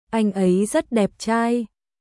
Anh ấy rất đẹp trai（アイン・エイ・ザッ・デップチャーイ）：